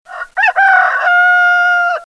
Rooster Calling 2
Category: Sound FX   Right: Personal